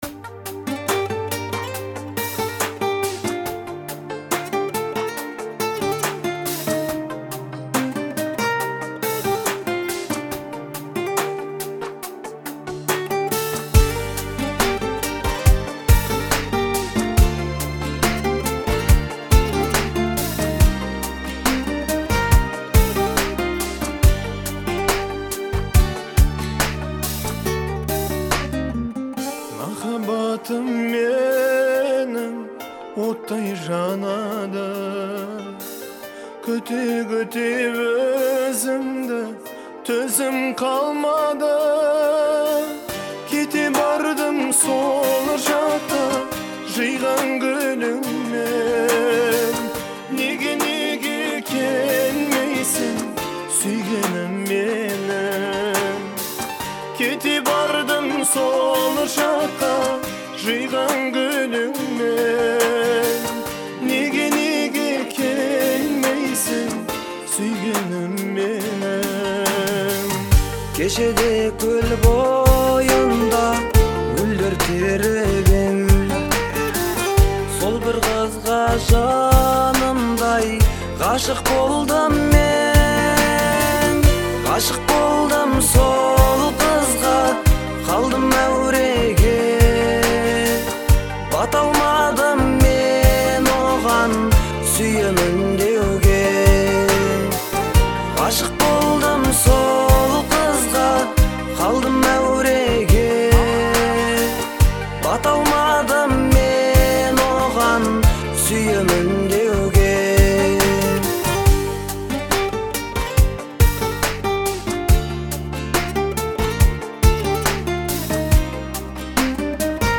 романтическая песня
которая сочетает в себе элементы поп и традиционной музыки.